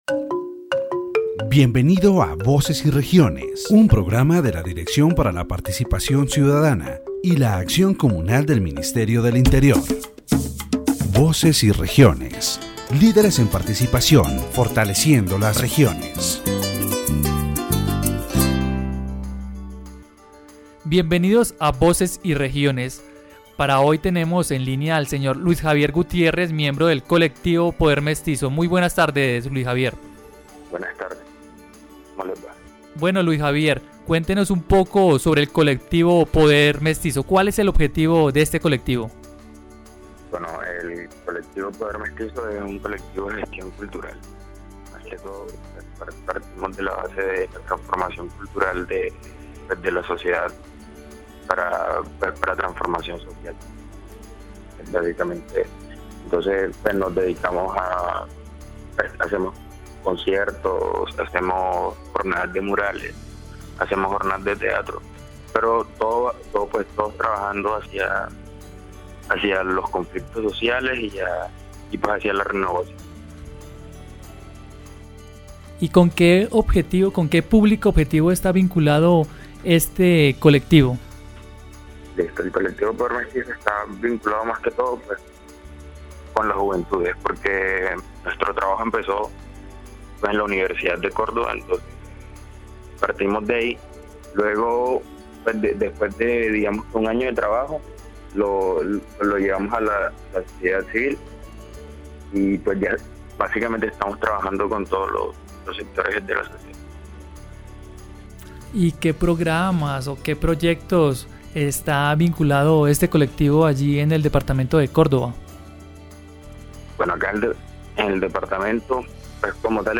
In this section of Voces y Regiones, the interviewee, a member of the Poder Mestizo collective, discusses the organization of youth communities and their role in transforming the territory. He highlights the importance of creating spaces for active participation, where young people can empower themselves and contribute to the development of their community.